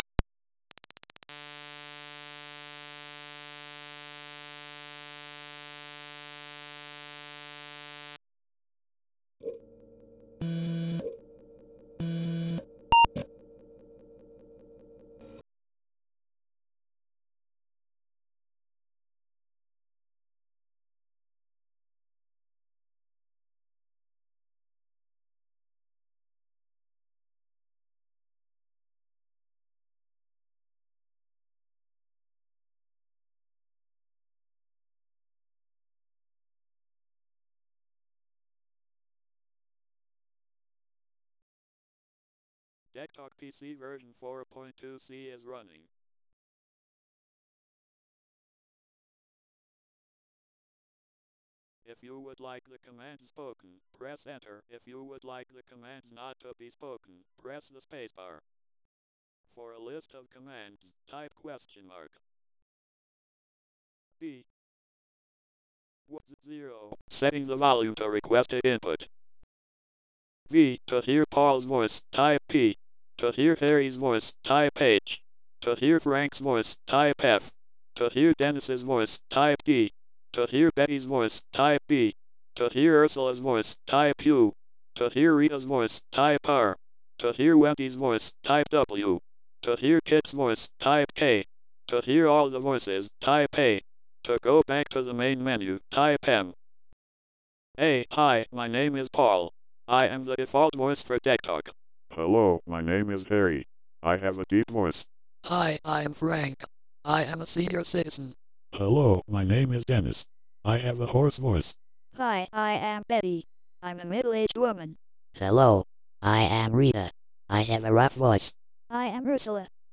Update on MAME DECtalk PC emulation. I implemented a hack to fix the broken DSP output.